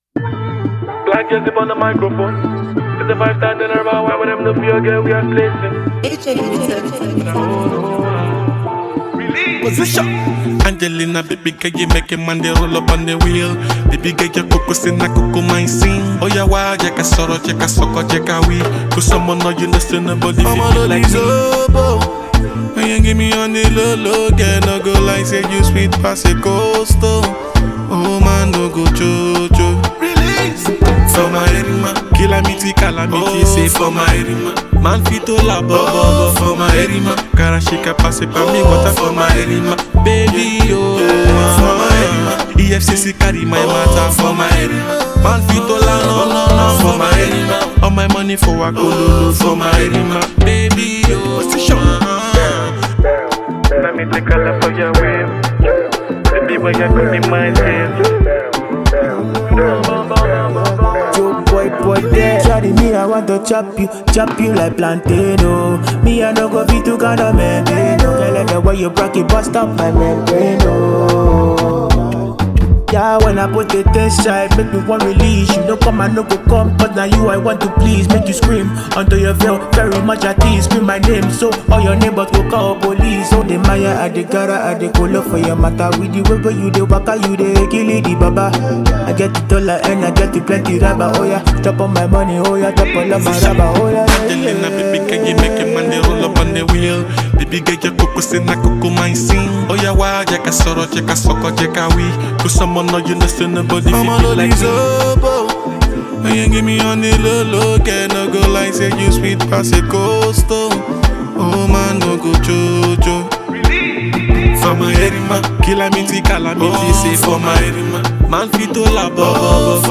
Reggae and Afrobeats music